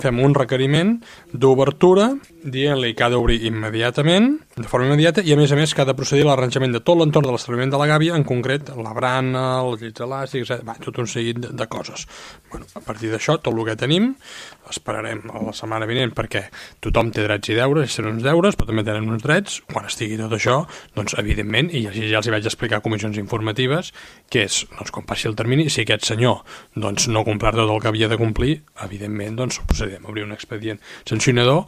Ho va explicar l’alcalde Marc Buch en l’última sessió plenària, on va donar compte de les actuacions realitzades davant els incompliments detectats en les últimes setmanes i que han fet aixecar la veu dels partits de l’oposició, que reclamen un major seguiment i control de les concessions municipals.